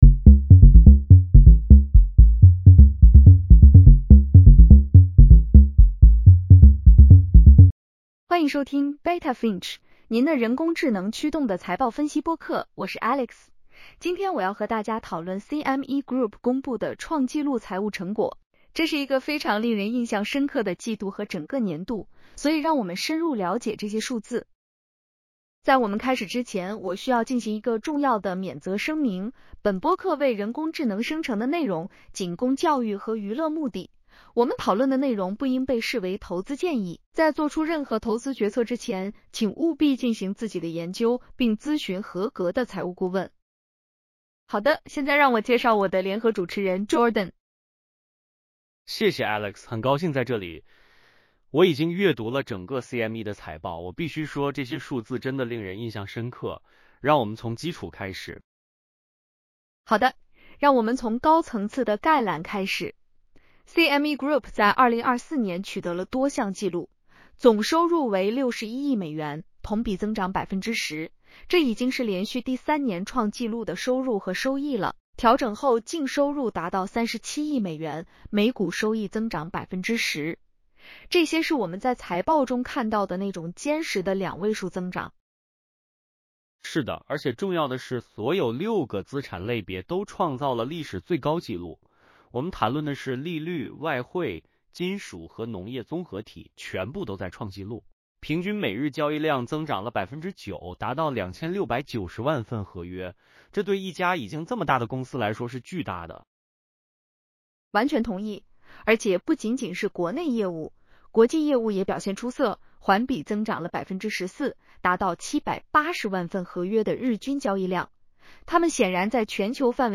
在我们开始之前，我需要进行一个重要的免责声明：本播客为人工智能生成的内容，仅供教育和娱乐目的。